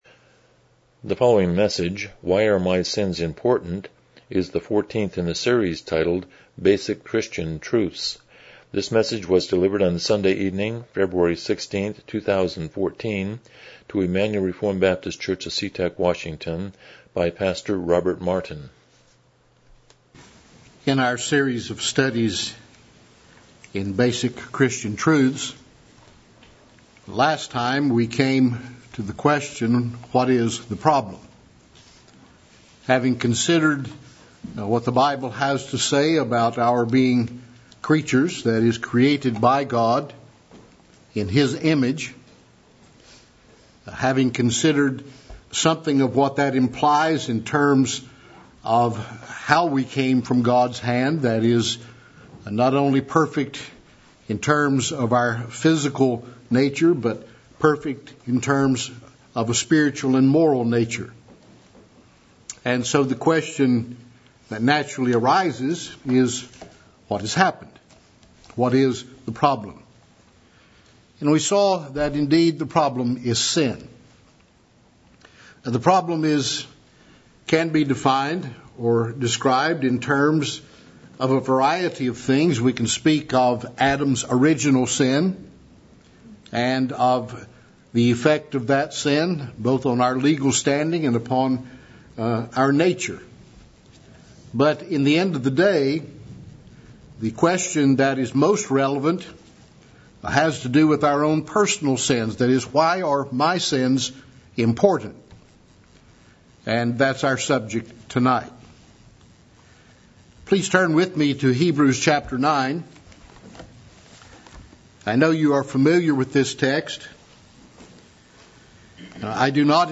Series: Basic Christian Truths Service Type: Evening Worship « 46 The Sermon on the Mount